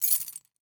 household
Keys Dropping on The Table